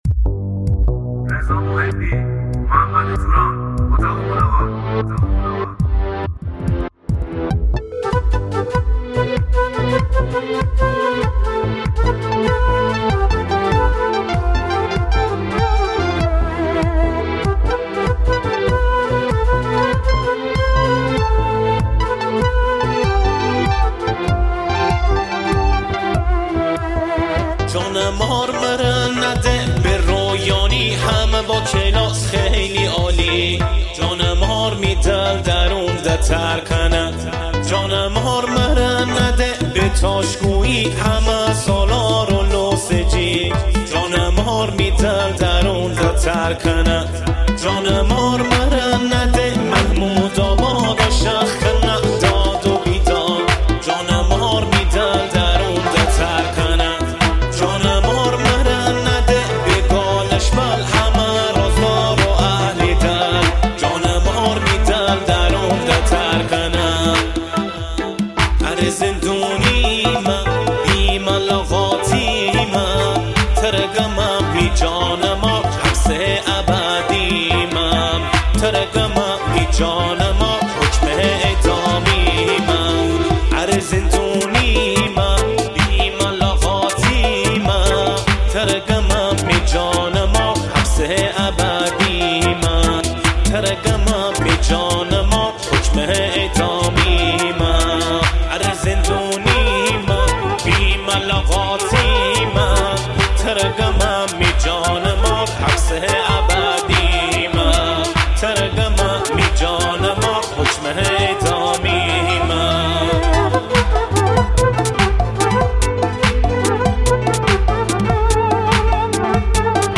دانلود آهنگ مازندرانی جدید و زیبا
موضوع : آهنگ غمگین , خوانندگان مازنی ,